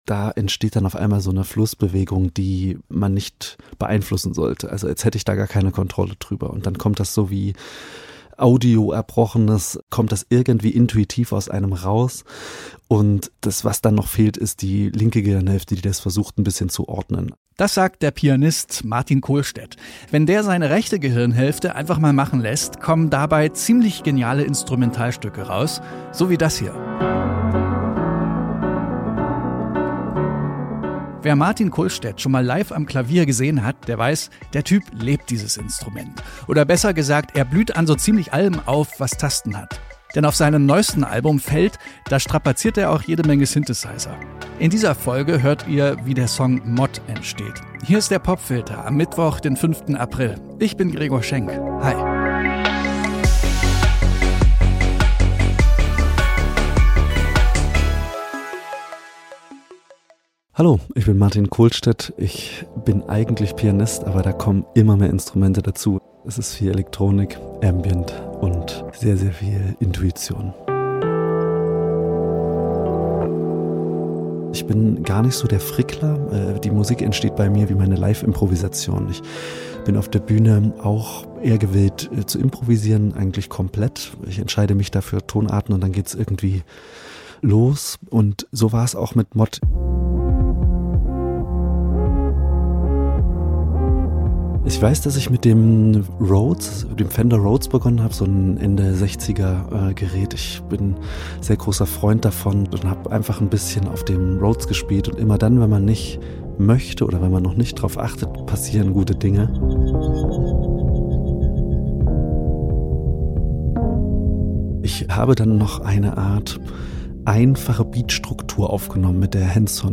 Elektronik, Ambient und sehr viel Intuition – das sind die Zutaten auf seinem neuen Albums „FELD“. Im Popfilter nimmt er uns mit in seine Weimarer Wohlfühloase und zeigt, wie der Song „MOD“ entstanden ist.